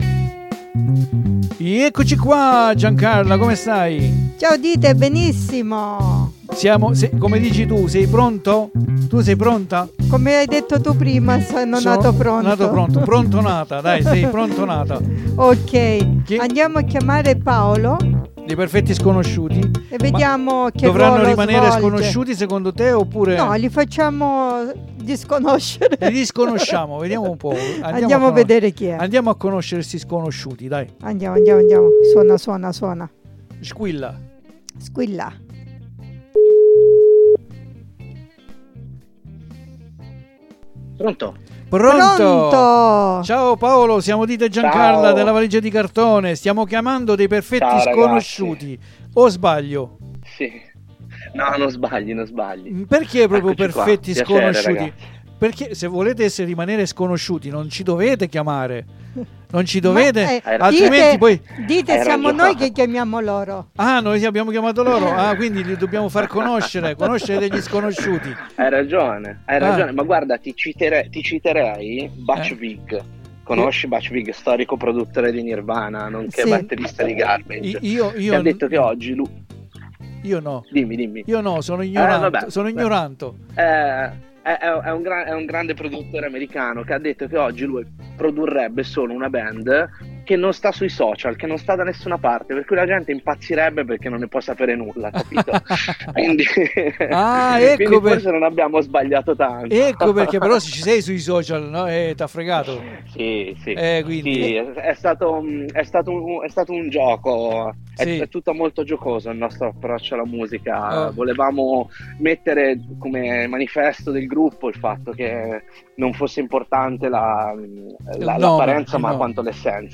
RAGAZZO PIENO DI RISORSE ANDIAMO AD ASCOLTARE COSA CI DICE AL TELEFONO!